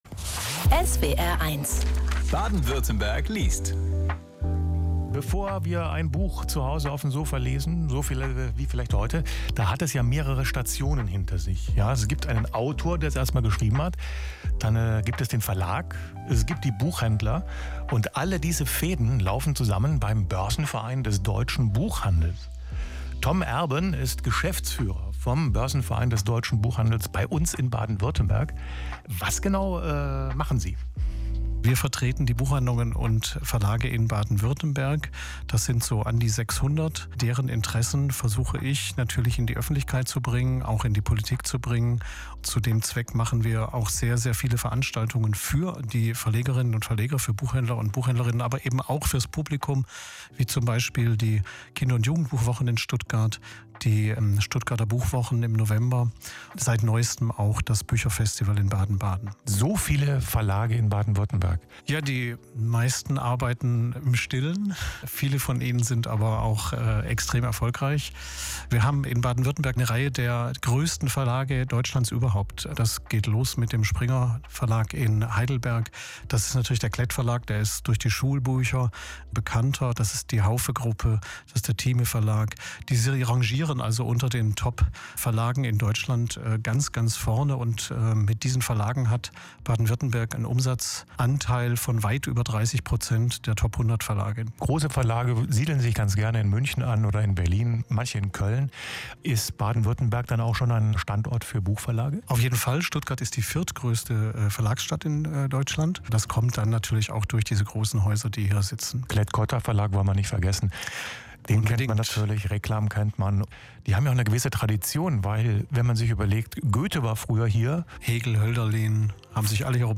SWR1 BW liest!